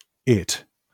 akcentowane enPR: ĭt, IPA/ɪt/, SAMPA/It/